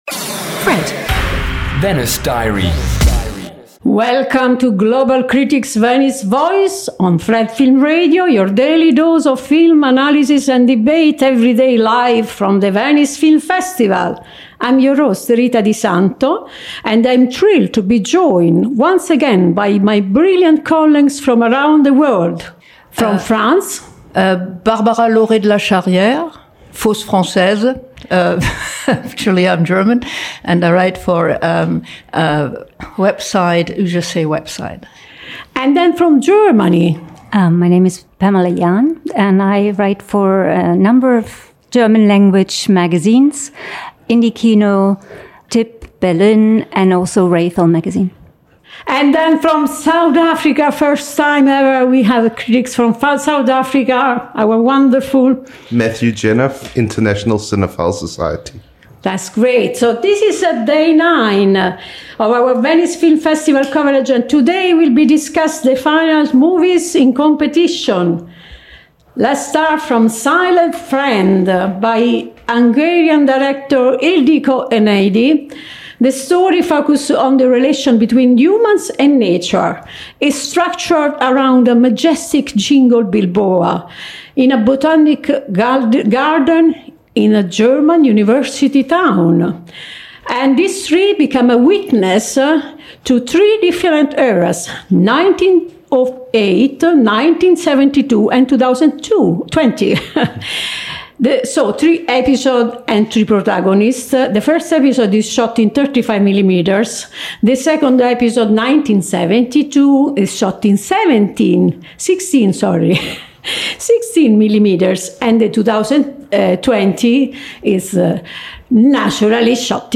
Each episode takes listeners inside the Festival with exclusive and thoughtful conversations with leading international film critics, and in-depth analysis of the year’s most anticipated films.